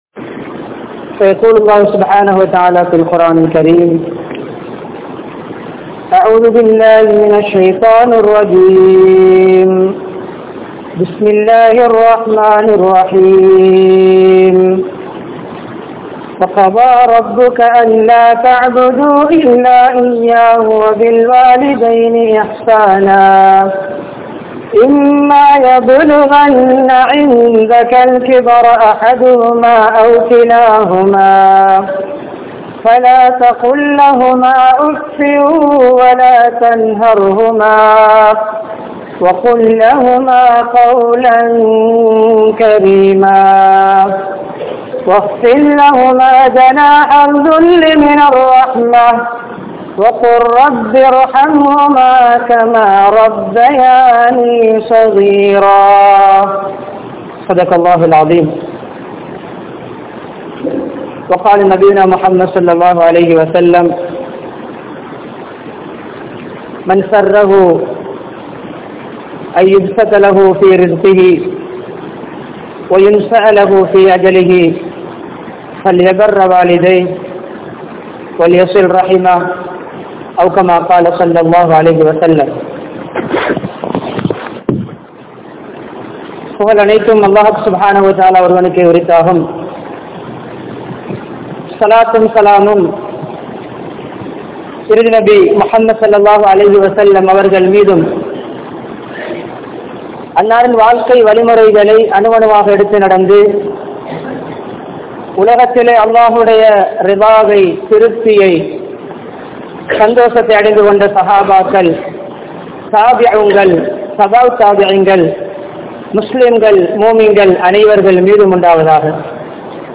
Kinniya, Noorullah Jumua Masjidh